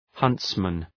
Προφορά
{‘hʌntsmən}